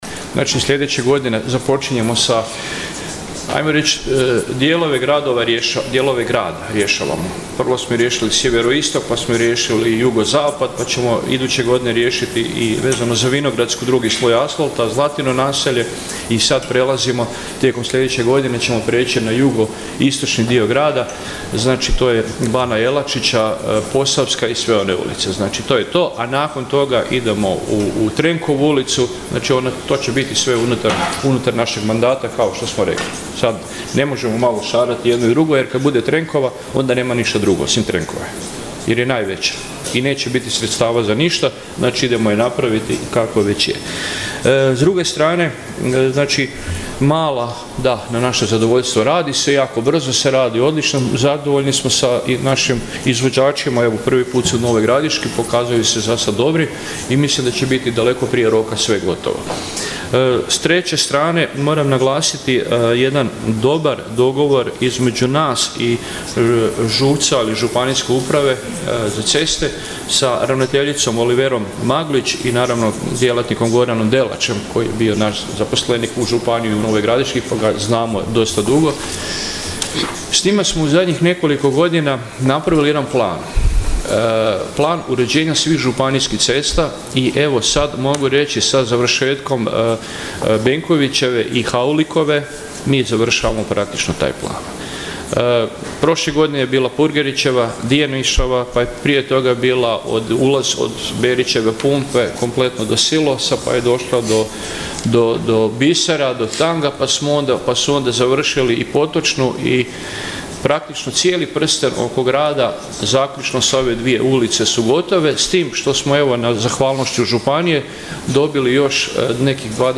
Aktualni sat 6. sjednice Gradskog vijeća Grada Nova Gradiška i ovoga je puta bio prilika gradskim vijećnicima da postave pitanja vezana uz svakodnevno funkcioniranje grada i rad gradske uprave. Na sva postavljena pitanja odgovarao je gradonačelnik Vinko Grgić.